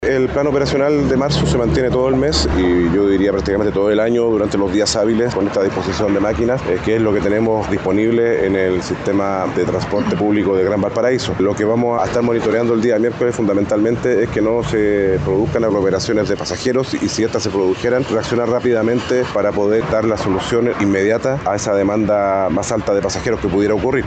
Frente a esto, el seremi de Transportes y Telecomunicaciones de la región de Valparaíso, Benigno Retamal, señaló que el día miércoles se monitoreará principalmente que no se produzcan aglomeraciones de pasajeros en los paraderos.